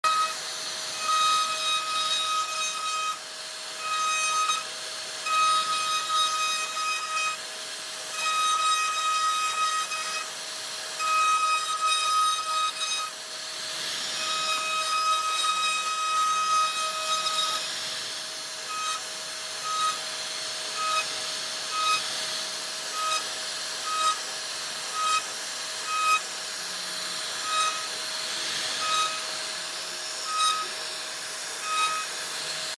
Watch the CNC machine glide sound effects free download
Watch the CNC machine glide through wood with smooth, perfect precision that’s deeply calming. No talking, no music — just the pure and satisfying ASMR sounds of carving.